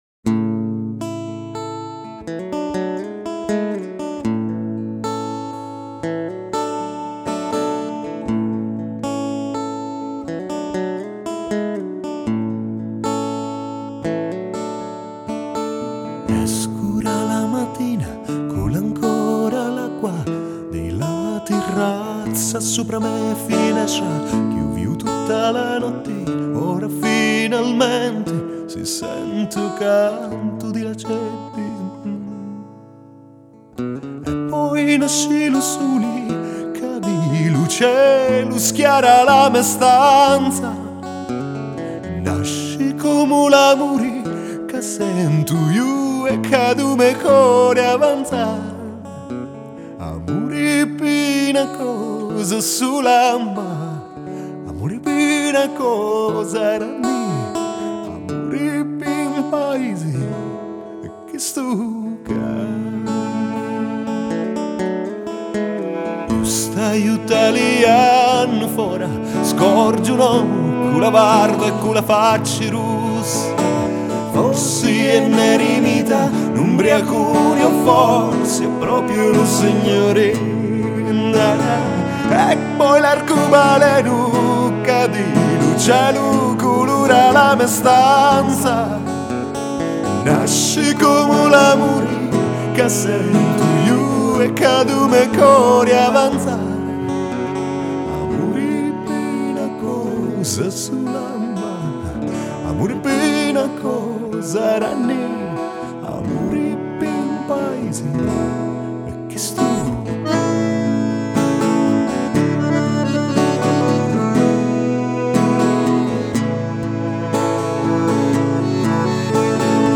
sound elettroacustico
chitarra
piano e fisarmonica